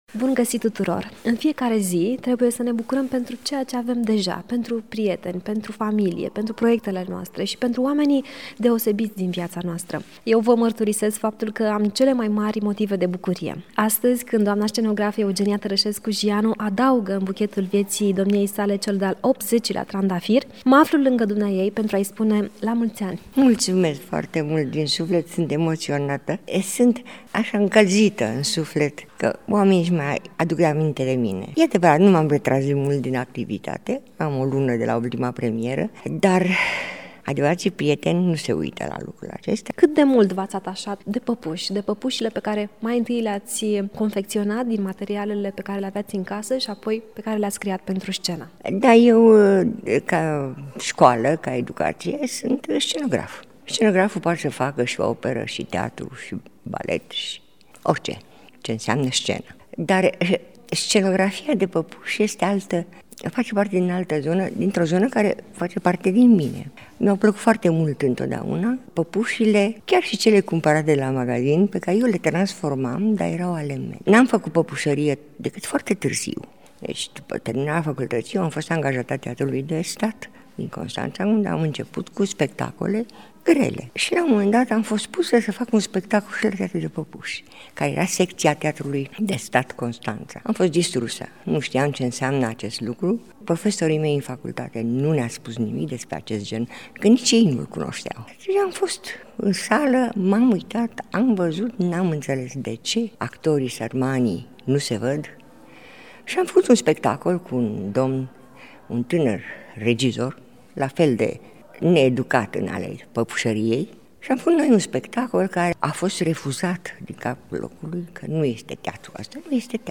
Ascultați un interviu emoționant